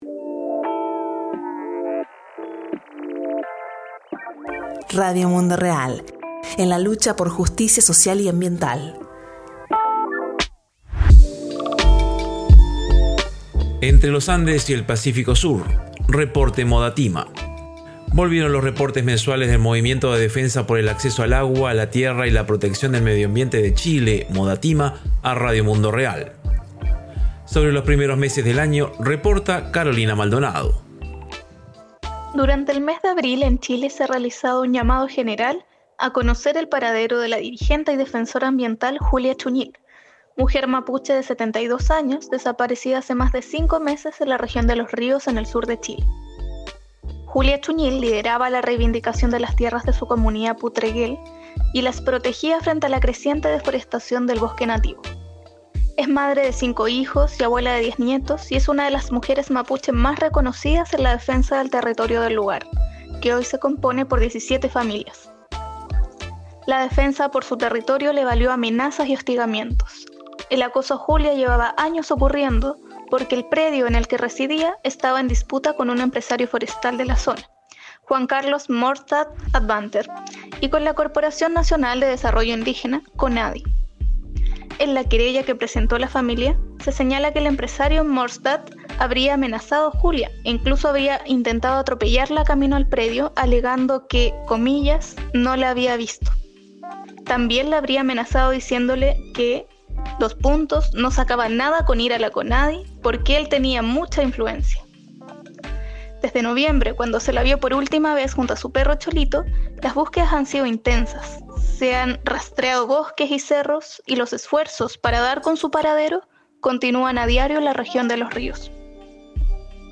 Entre los Andes y el Pacífico Sur: Reporte MODATIMA - Radio Mundo Real